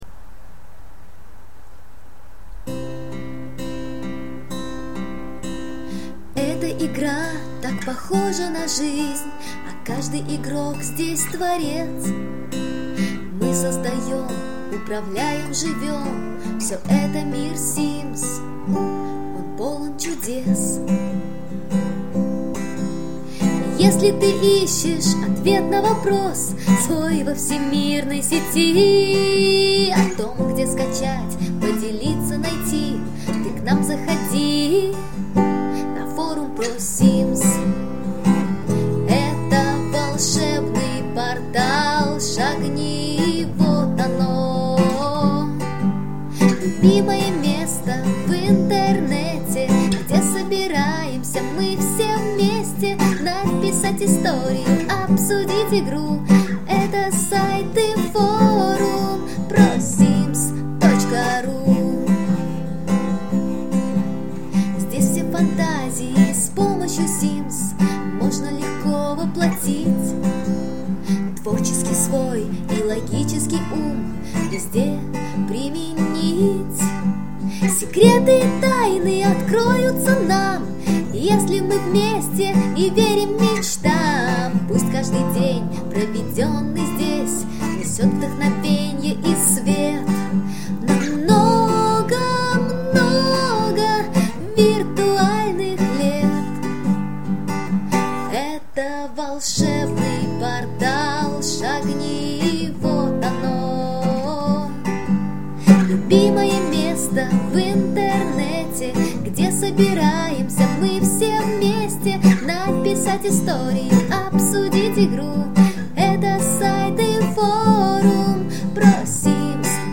Задорная и весёлая мелодия
hymn_prosims_acoustic_v1.mp3